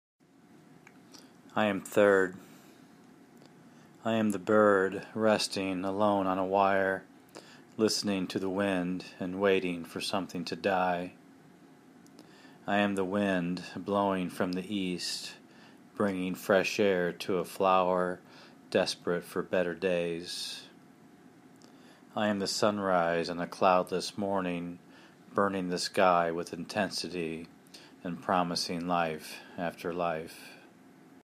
It’s always nice to hear someone read their own work.